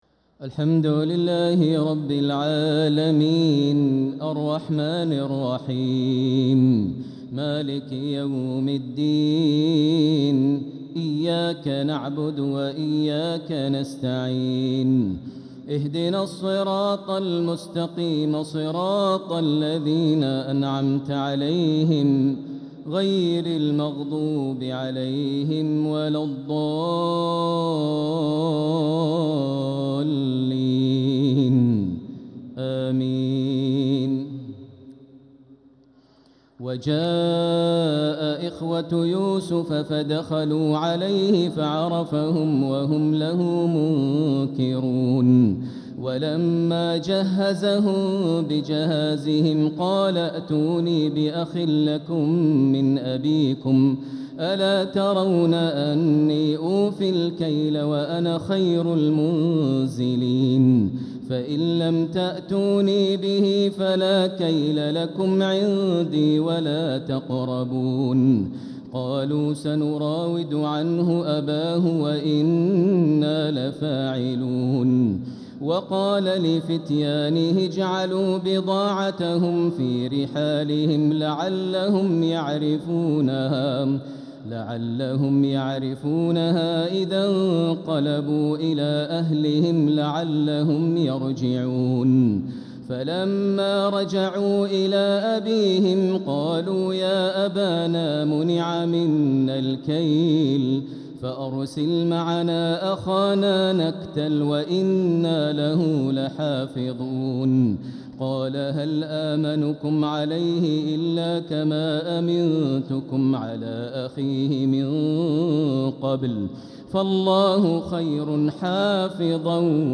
تراويح ليلة 17 رمضان 1446هـ من سورتي يوسف (58-111) و الرعد (1-18) | Taraweeh 17th night Ramadan 1446H Surat Yusuf and Ar-Rad > تراويح الحرم المكي عام 1446 🕋 > التراويح - تلاوات الحرمين